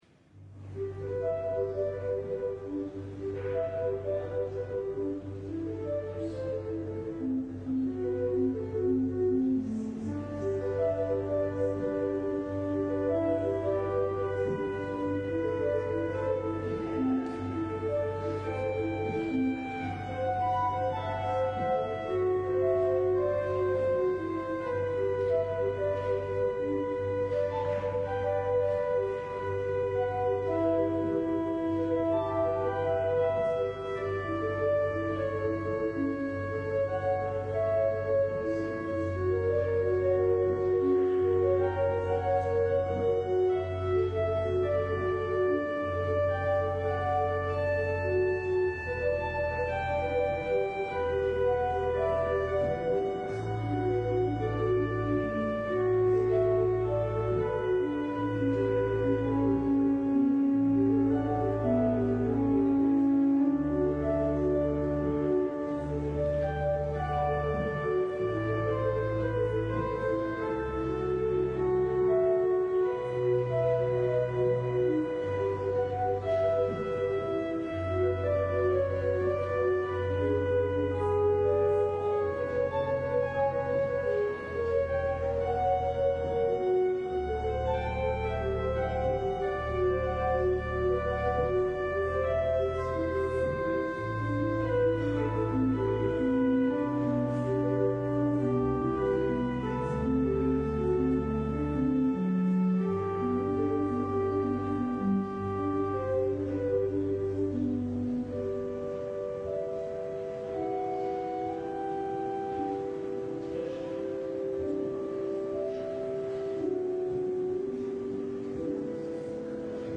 LIVE Morning Worship Service - The Prophets and the Kings: The Chariots of Fire